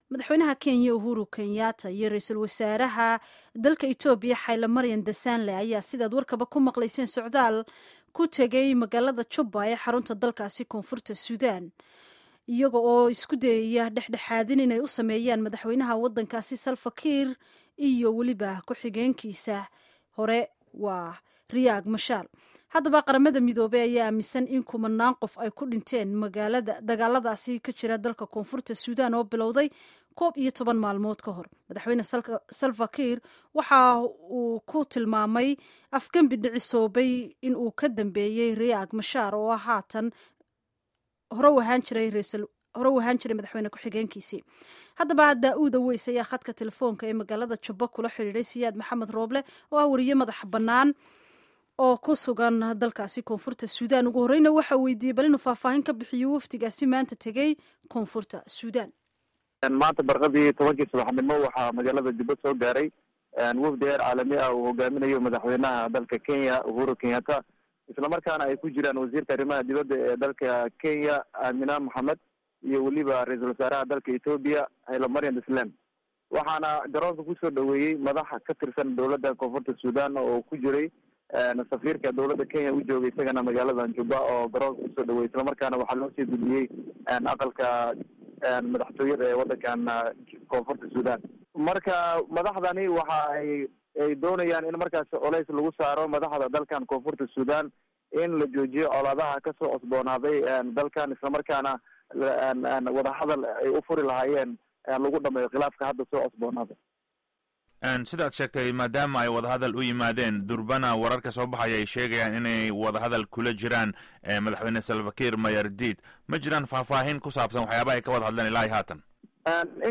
Waraysi ku saabsan K.Suudaan